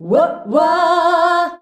UAH-UAAH F.wav